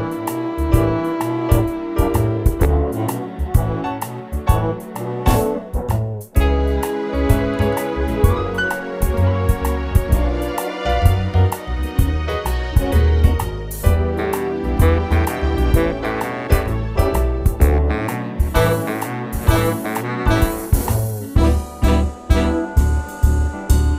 One Semitone Down Jazz / Swing 3:39 Buy £1.50